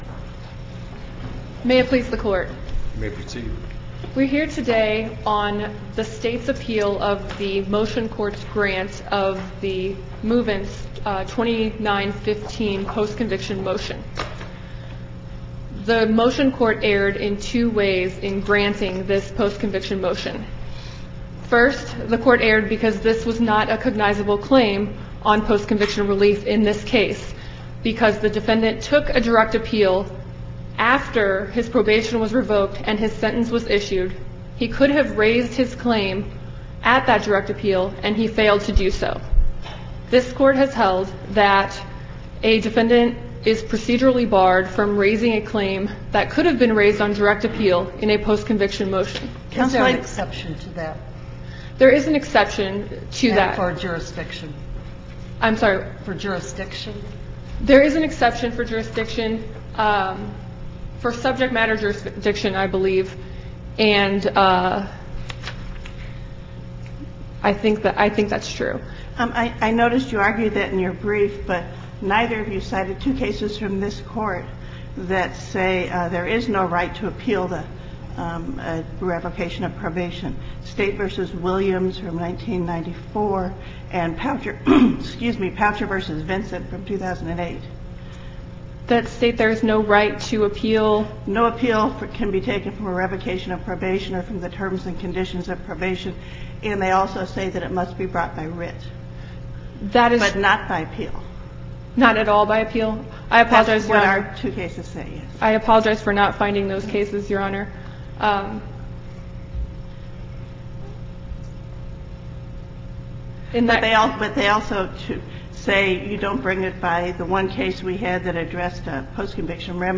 MP3 audio file of arguments in SC96754